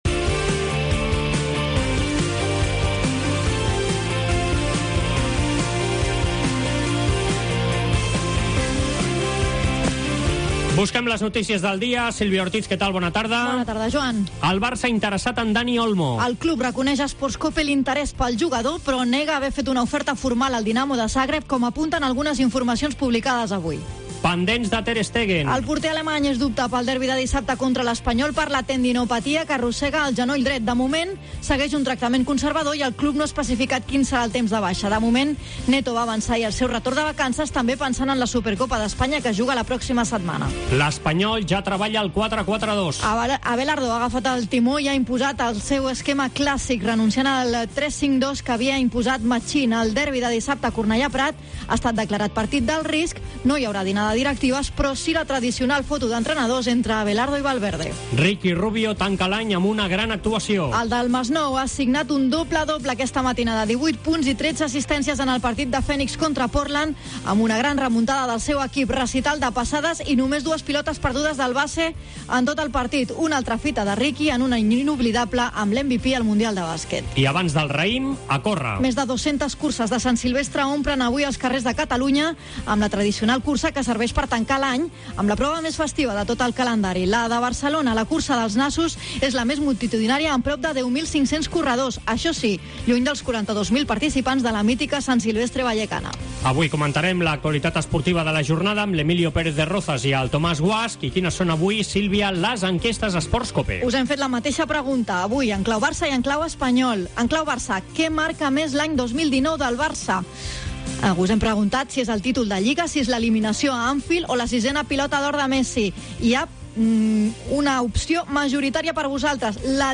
entrevista al jugador del Espanyol Bernardo